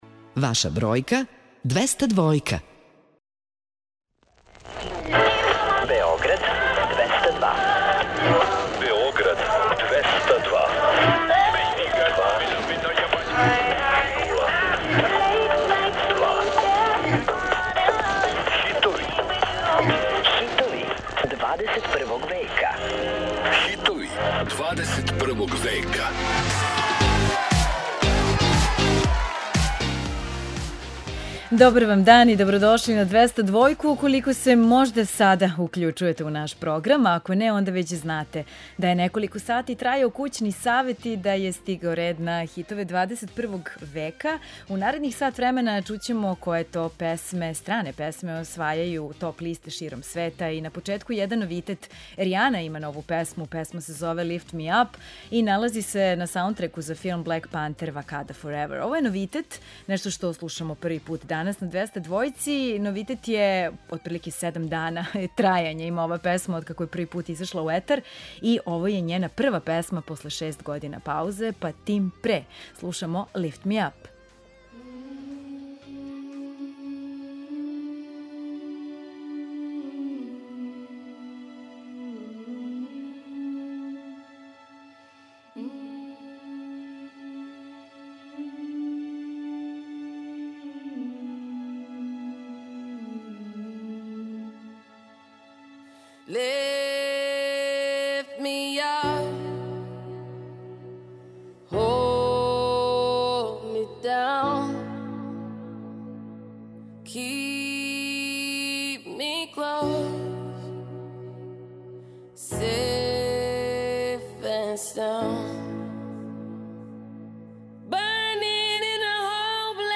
Čućete pesme koje se nalaze na vrhovima svetskih top lista.